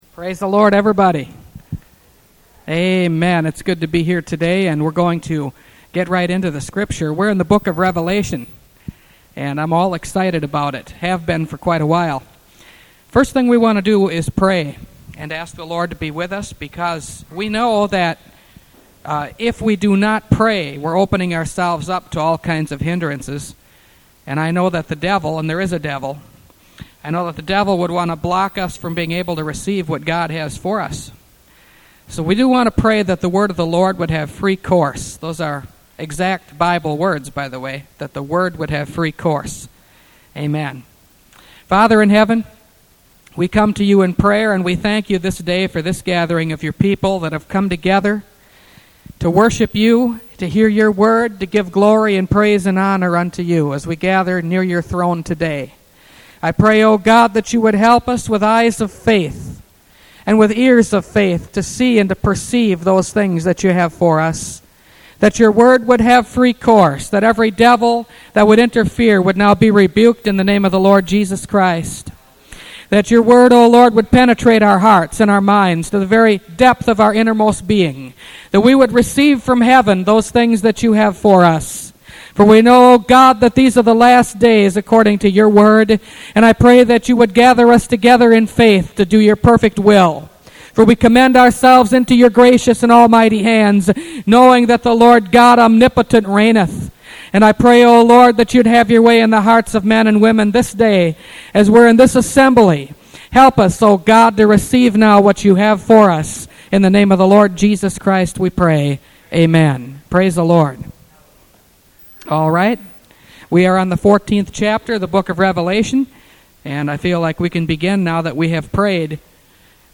Revelation Series – Part 33 – Last Trumpet Ministries – Truth Tabernacle – Sermon Library